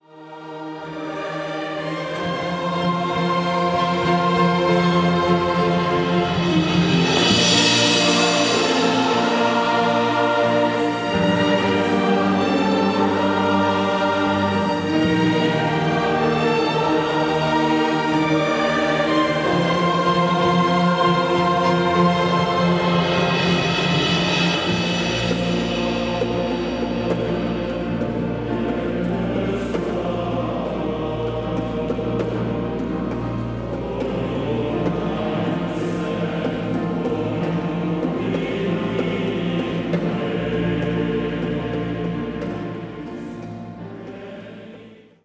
• kurzweilige Zusammenstellung verschiedener Live-Aufnahmen